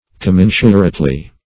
Search Result for " commensurately" : The Collaborative International Dictionary of English v.0.48: Commensurately \Com*men"su*rate*ly\, adv. 1.